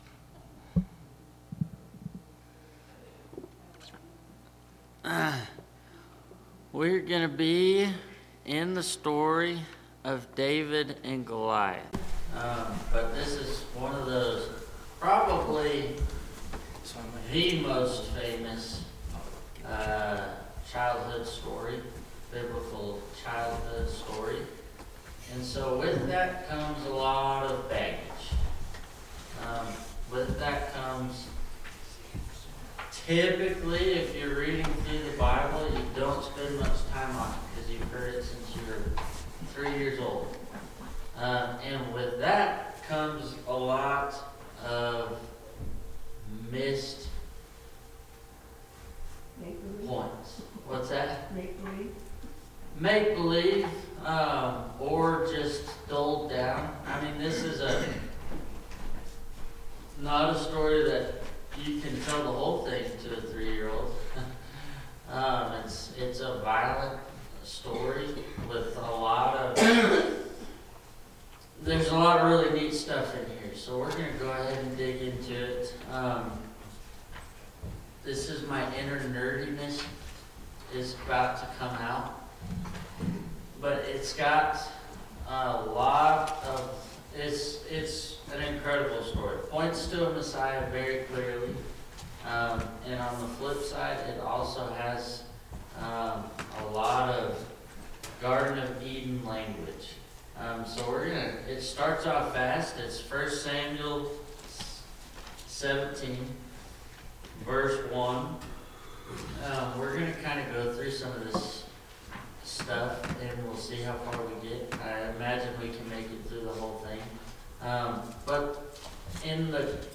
Sunday AM Bible Class